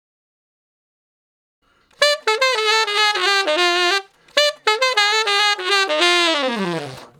066 Ten Sax Straight (D) 23.wav